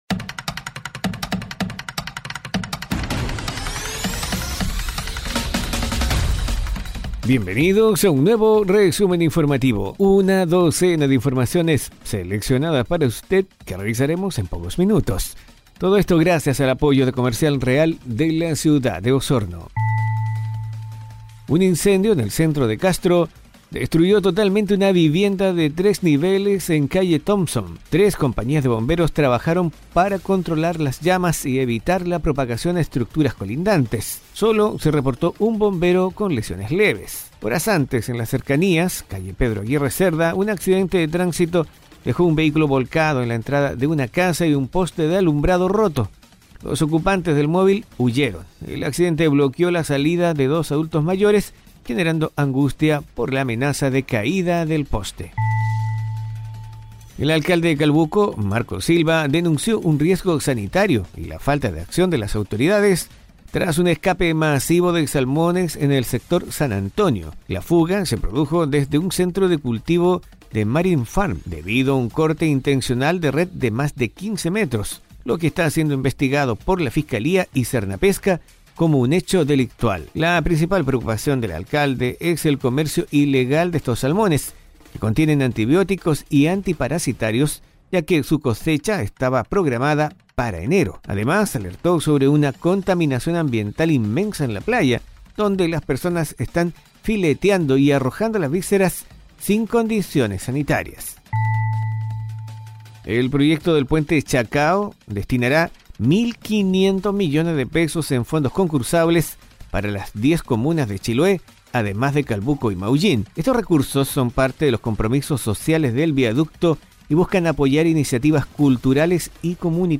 🎙 ¡Tu resumen informativo en podcast está aquí! 🗞 🚀 Conoce las noticias más relevantes de la Región de Los Lagos de forma ágil y breve.